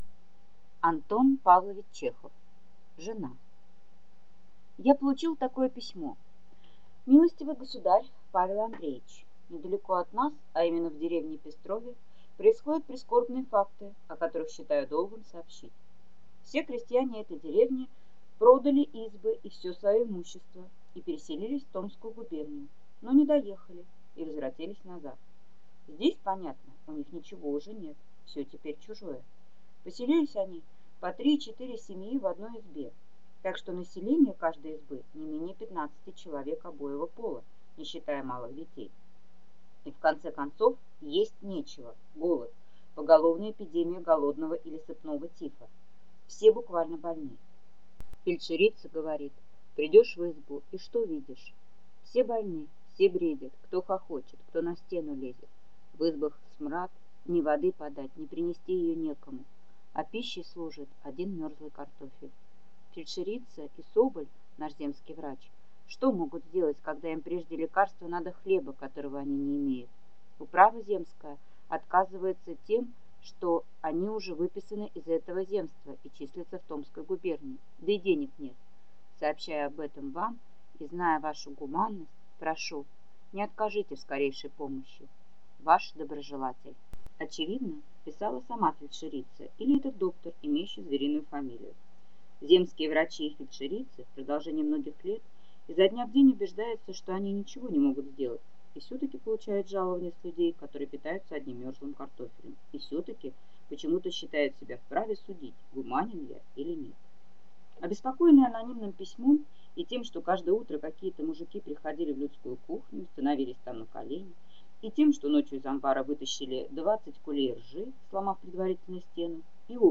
Аудиокнига Жена | Библиотека аудиокниг